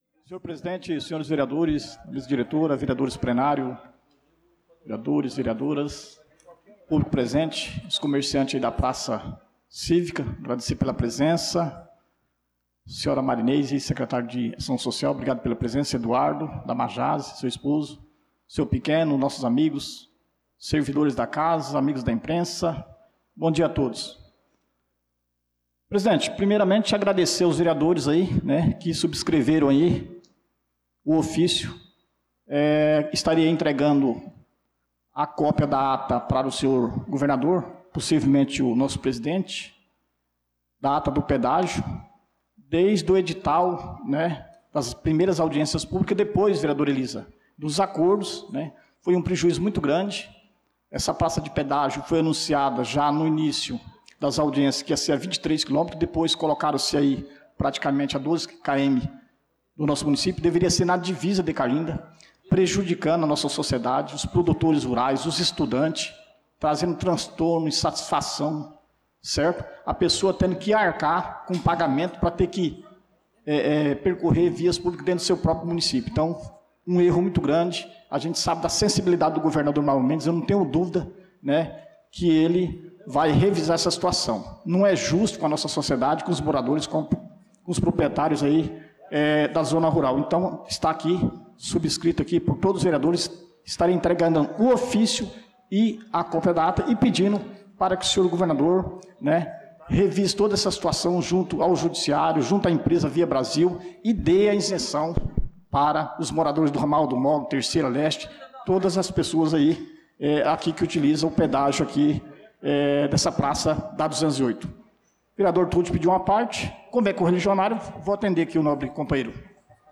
Pronunciamento do vereador Dida Pires na Sessão Ordinária do dia 02/06/2025